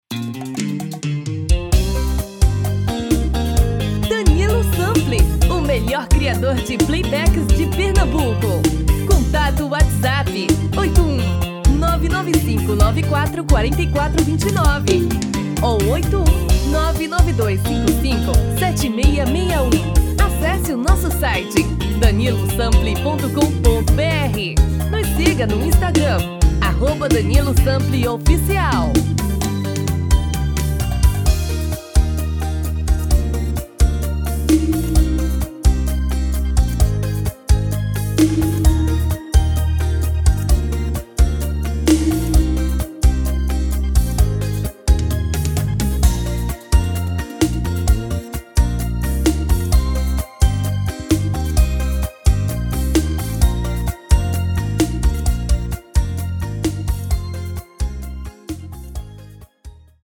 DEMO 1: tom original DEMO 2: indisponivel